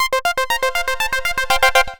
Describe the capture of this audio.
This file is an audio rip from a(n) Nintendo DS game.